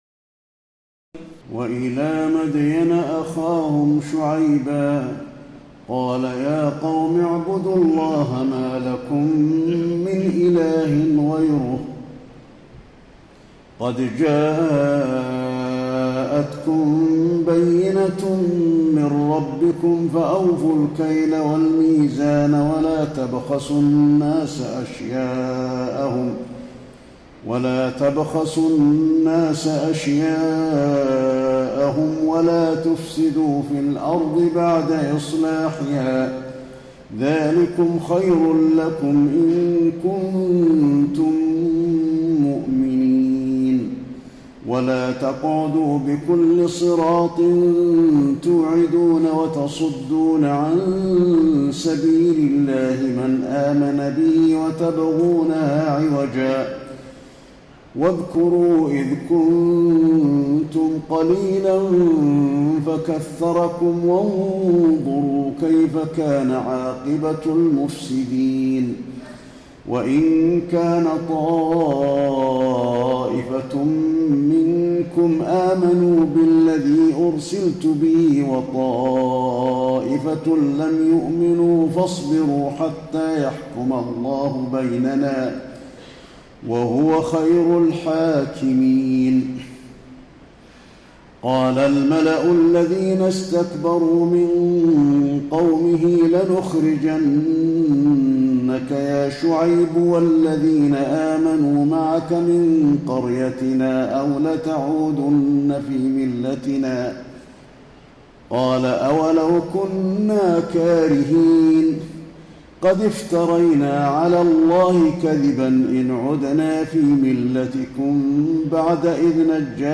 تراويح الليلة التاسعة رمضان 1432هـ من سورة الأعراف (85-170) Taraweeh 9 st night Ramadan 1432H from Surah Al-A’raf > تراويح الحرم النبوي عام 1432 🕌 > التراويح - تلاوات الحرمين